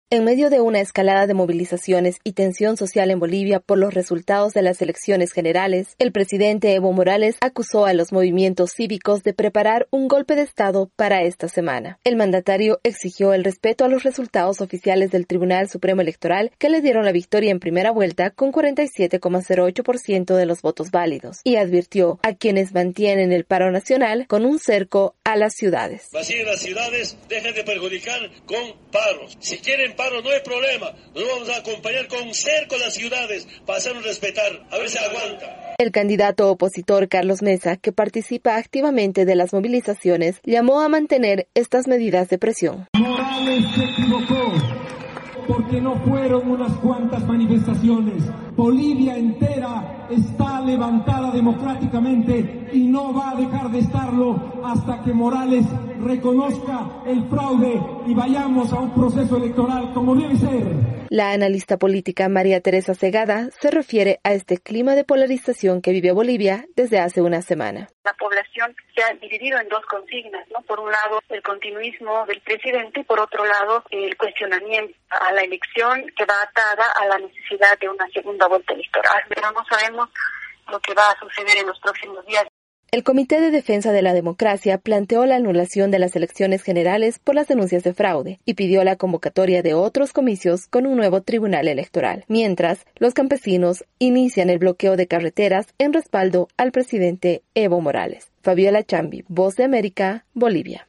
VOA: Informe desde Bolivia